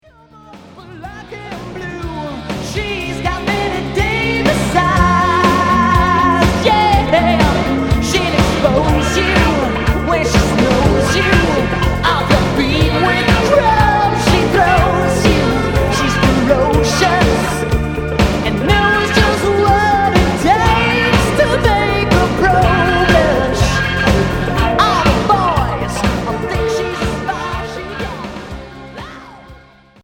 Hard FM